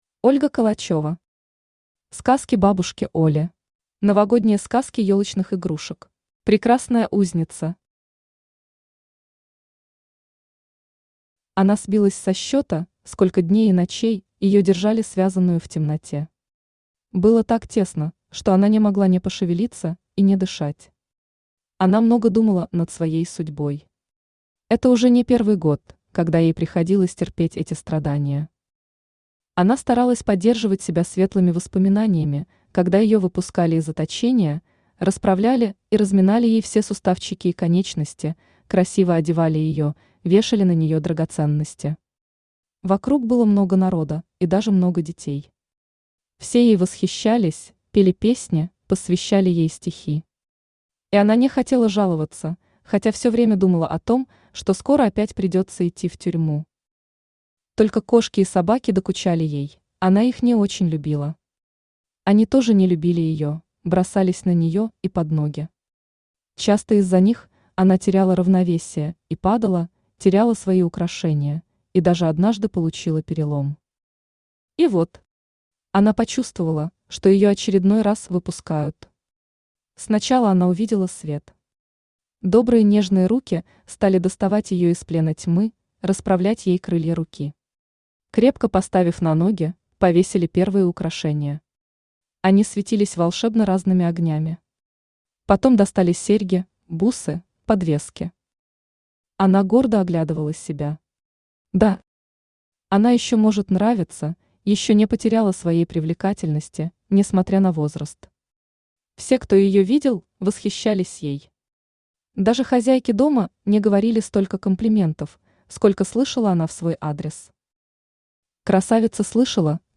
Аудиокнига Сказки бабушки Оли | Библиотека аудиокниг
Aудиокнига Сказки бабушки Оли Автор Ольга Калачева Читает аудиокнигу Авточтец ЛитРес.